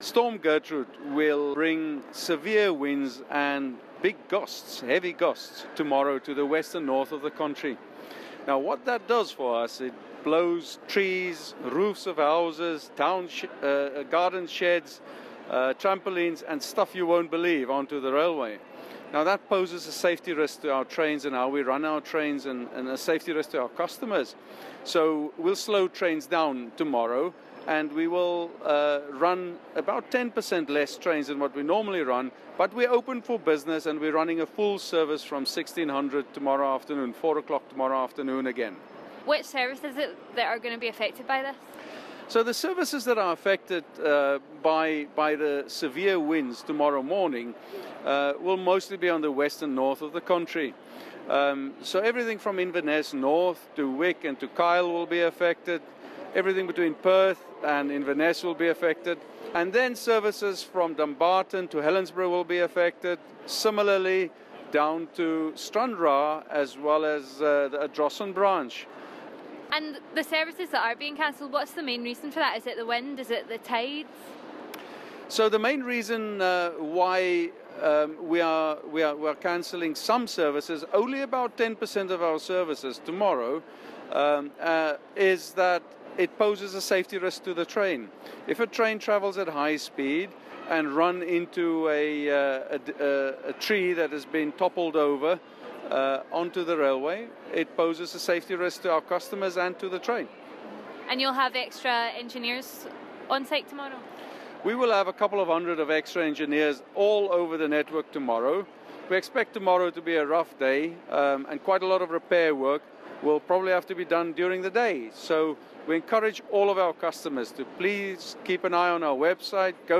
at Glasgow Central Station